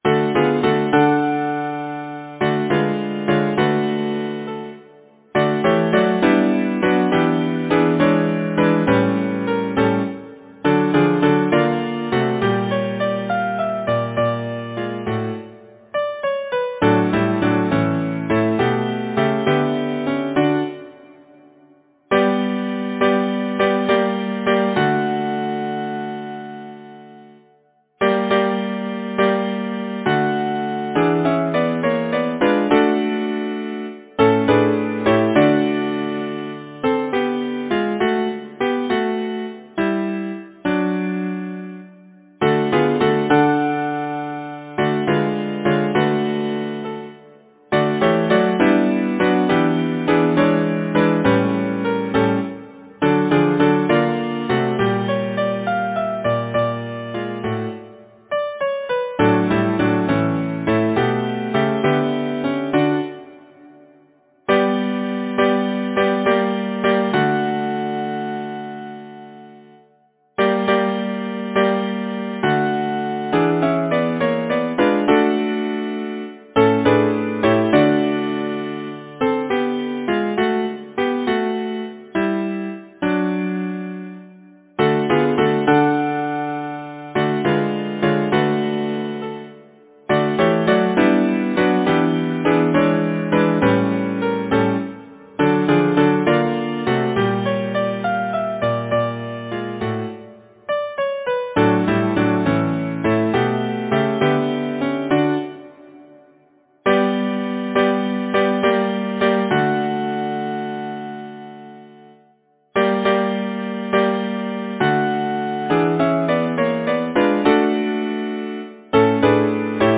Title: Snow-flakes Composer: Arthur Cottam Lyricist: Henry Wadsworth Longfellow Number of voices: 4vv Voicing: SATB, minor T divisi Genre: Secular, Partsong
Language: English Instruments: A cappella